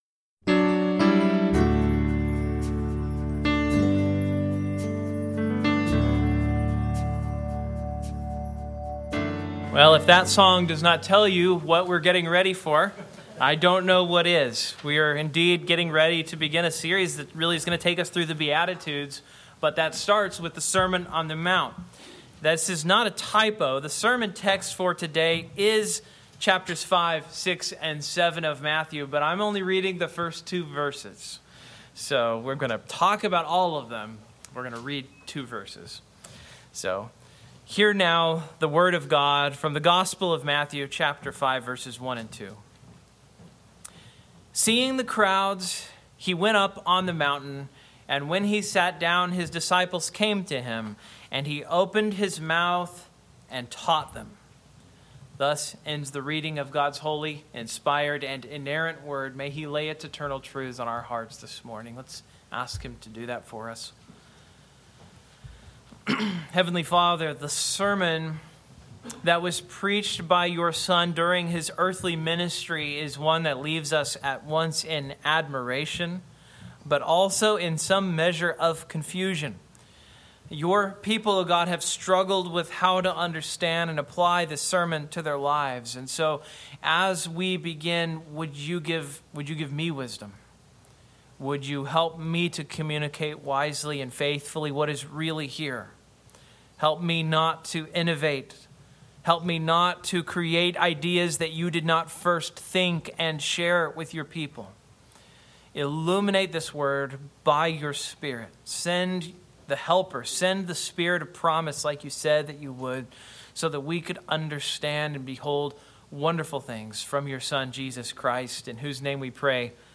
Matthew 5:1-7:29 Service Type: Morning « Old Testament #11